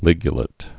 (lĭgyə-lĭt, -lāt)